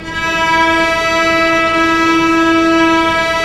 Index of /90_sSampleCDs/Roland LCDP13 String Sections/STR_Vcs FX/STR_Vcs Sul Pont